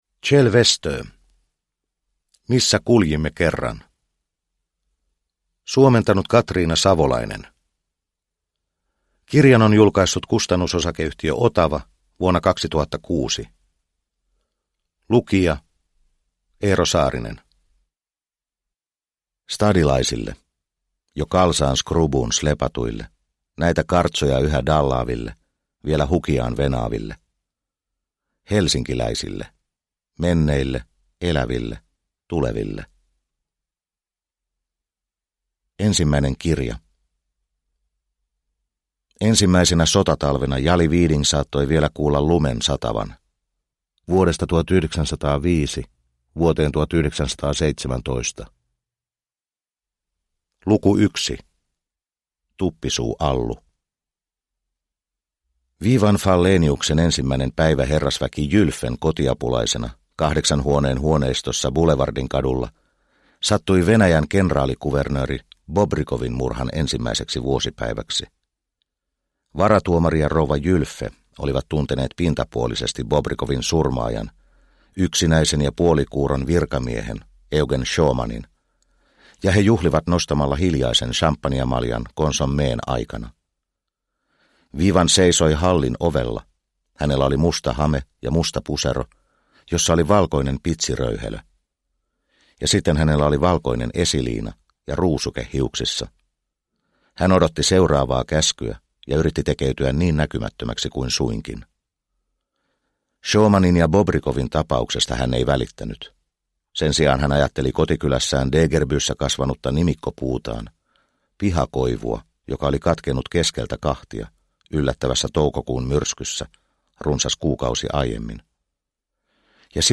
Missä kuljimme kerran – Ljudbok – Laddas ner